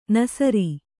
♪ nasari